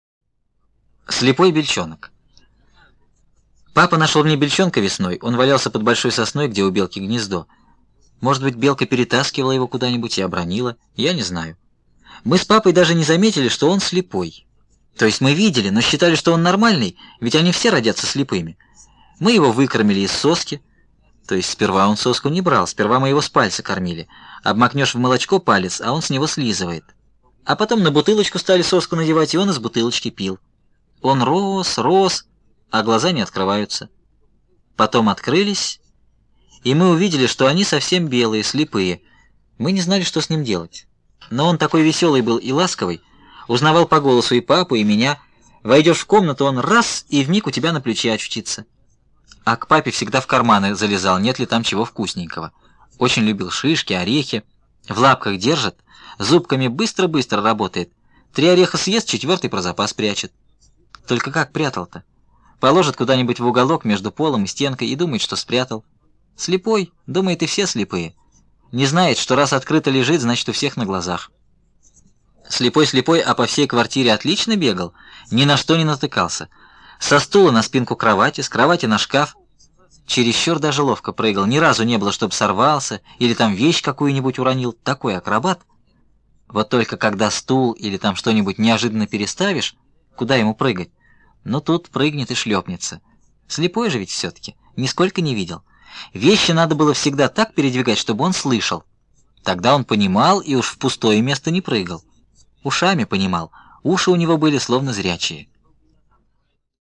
Аудиорассказ «Слепой бельчонок»